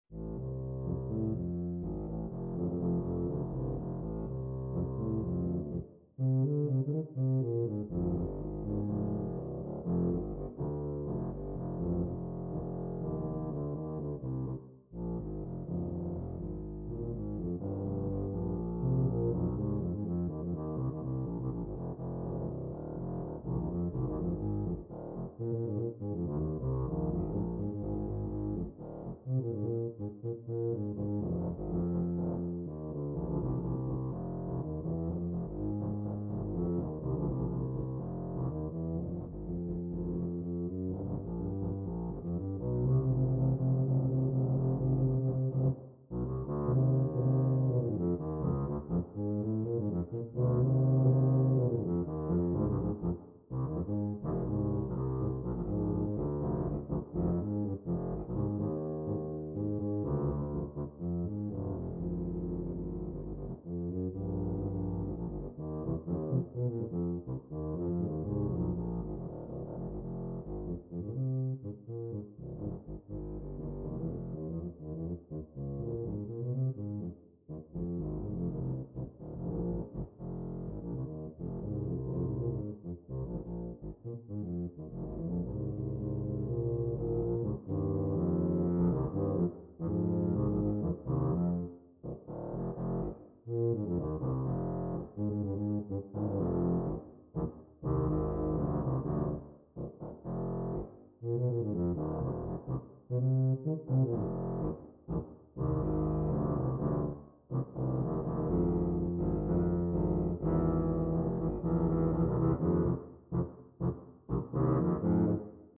Gattung: Für 2 Tuben
Besetzung: Instrumentalnoten für Tuba